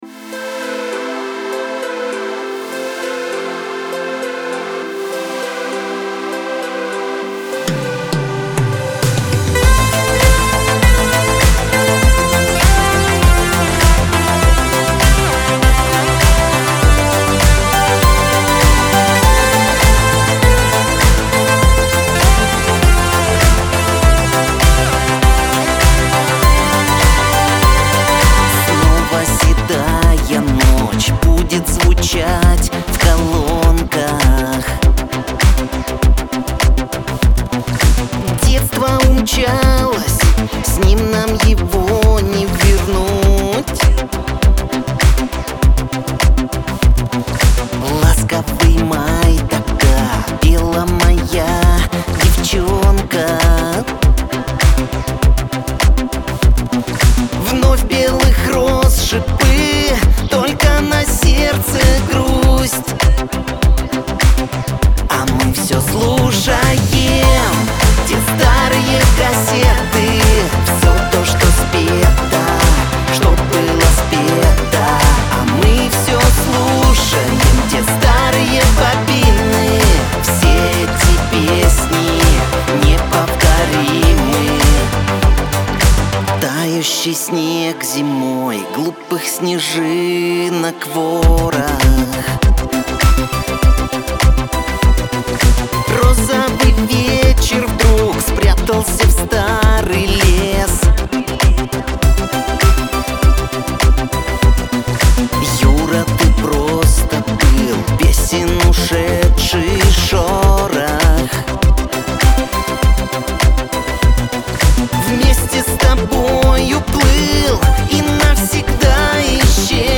pop
диско